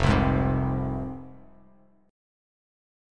Fm Chord
Listen to Fm strummed